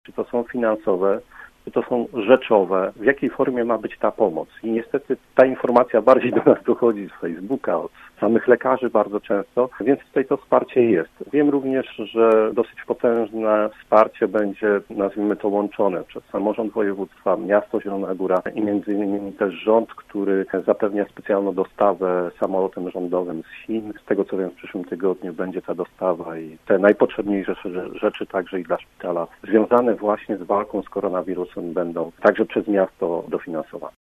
Przewodniczący Rady Miasta Zielona Góra o wsparciu dla Szpitala Uniwersyteckiego. Piotr Barczak, w Rozmowie Punkt 9 wskazywał, że w obliczu pandemii koronawirusa konieczna jest pomoc lecznicy.